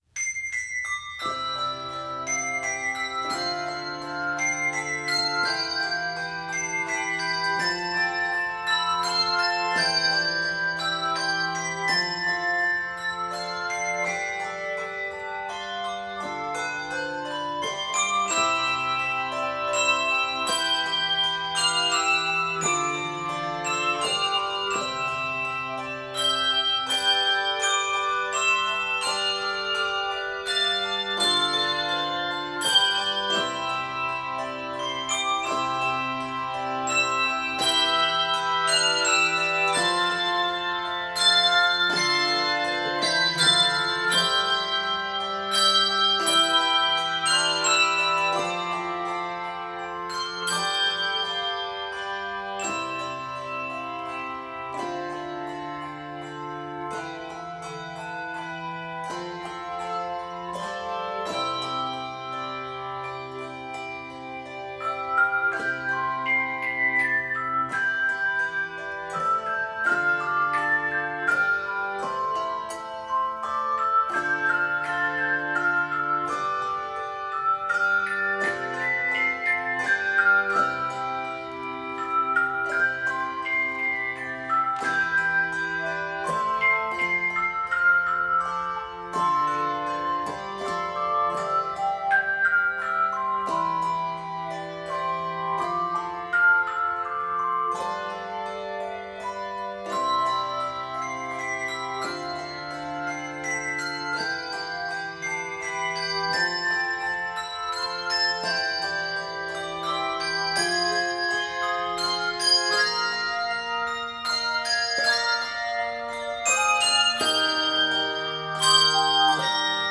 Keys of C Major and Eb Major.